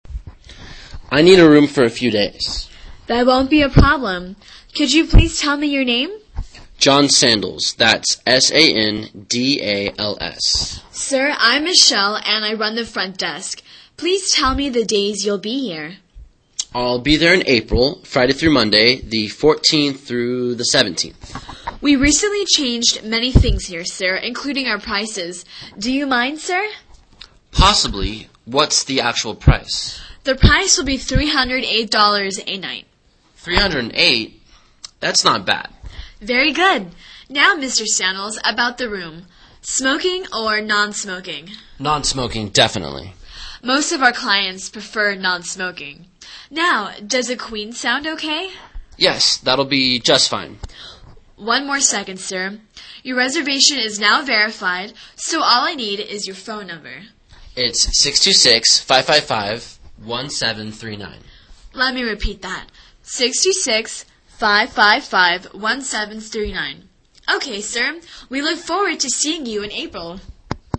英语对话之旅馆预订-5 听力文件下载—在线英语听力室